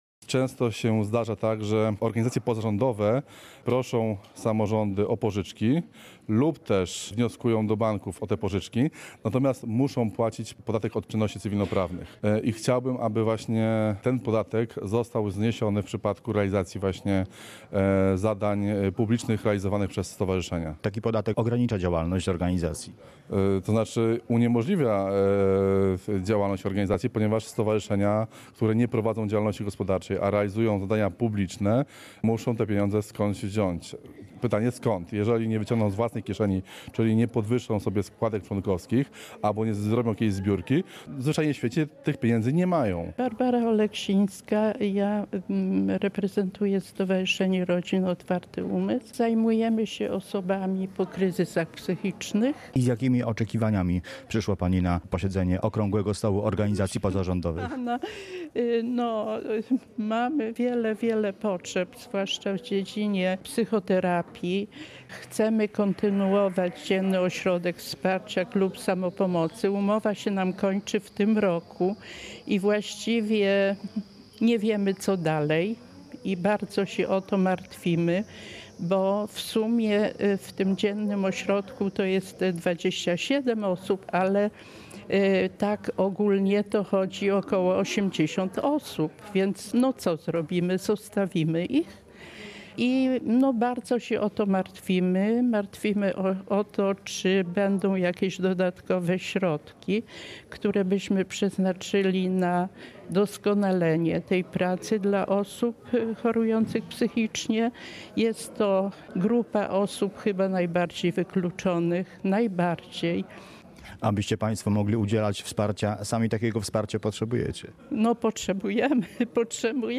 Relacja